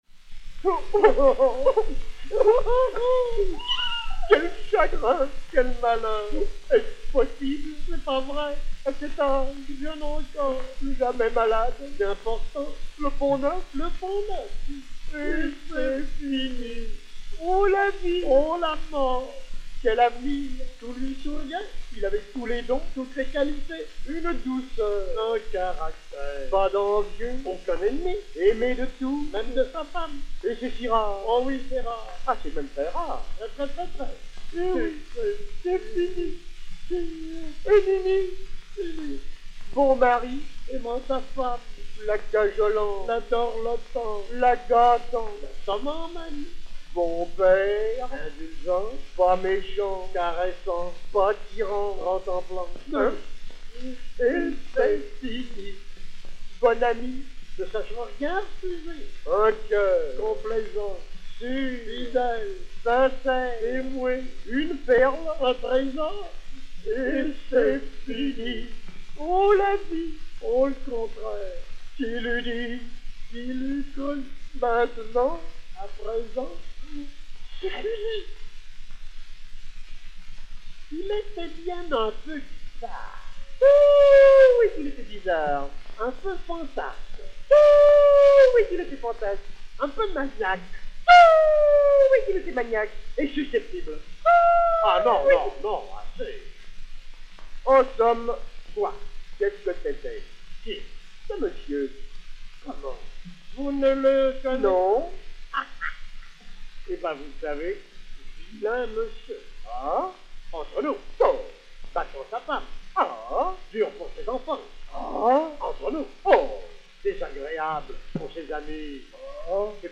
dialogue (Félix Galipaux)
Félix Galipaux et Koval du Théâtre des Variétés